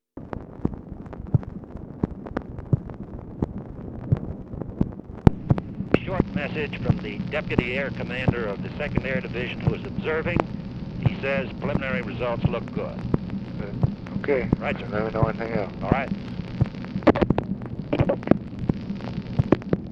Conversation with CYRUS VANCE, June 17, 1965
Secret White House Tapes